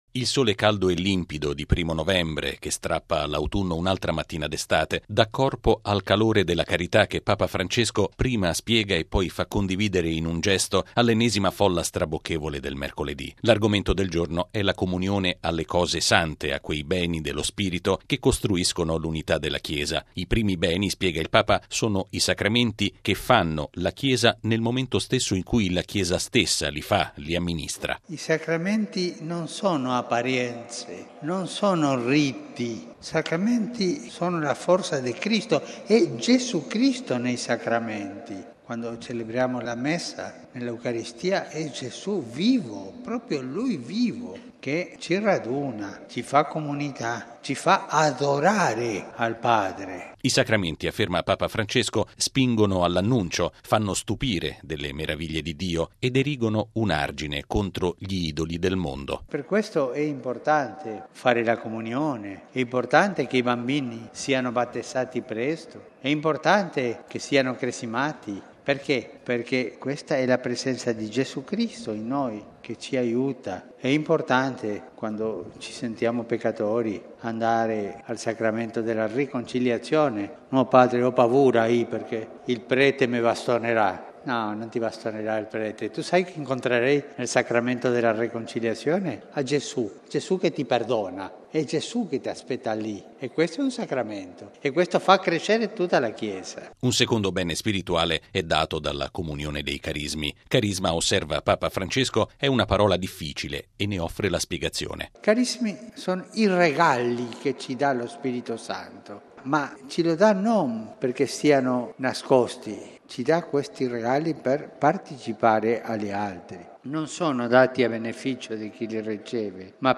Papa Francesco lo ha affermato all’udienza generale di questa mattina, presieduta in Piazza San Pietro di fronte a circa 80 mila persone. Il Papa ha riflettuto sulla comunione ai “beni spirituali” – cioè i Sacramenti, i carismi e la carità – e ha terminato chiedendo alla folla una preghiera per la guarigione di una bambina affetta da una gravissima malattia, salutata prima dell’udienza.